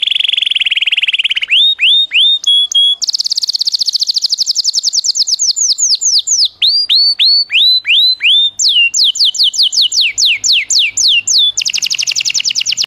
ringtone pajarito 2